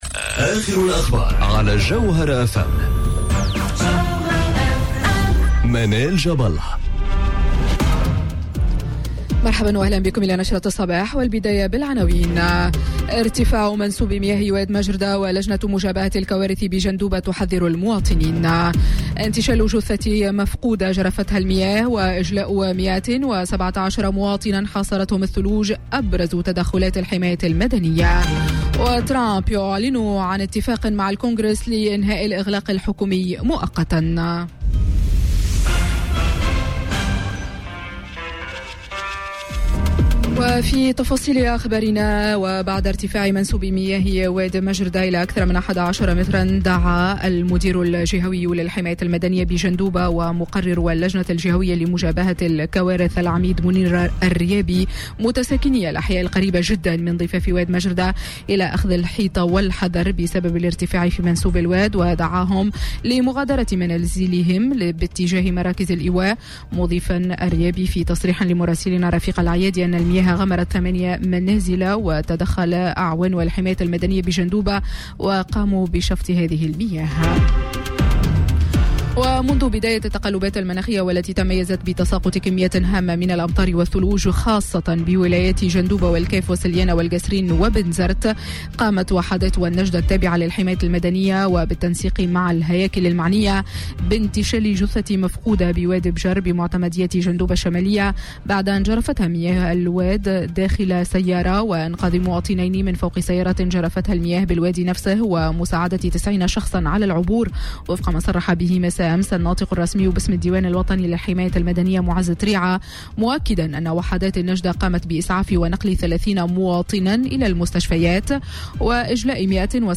نشرة أخبار السابعة صباحا ليوم السبت 26 جانفي 2019